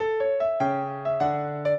piano
minuet6-11.wav